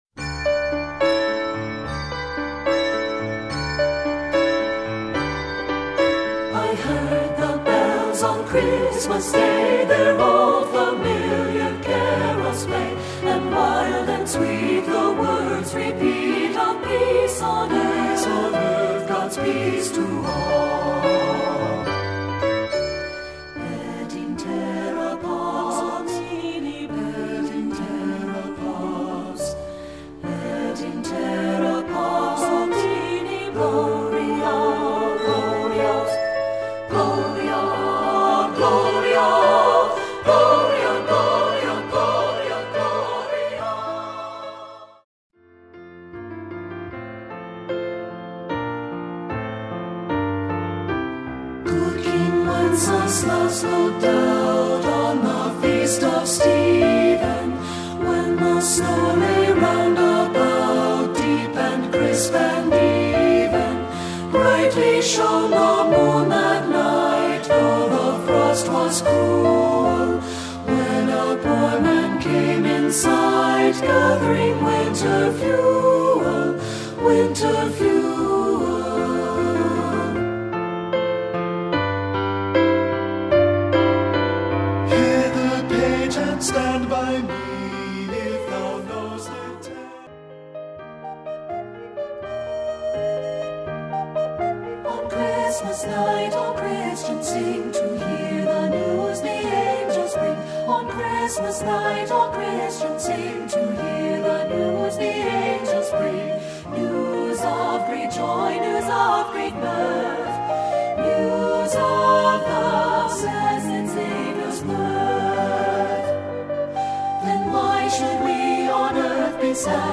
Composer: Traditional English Carols
Voicing: SAT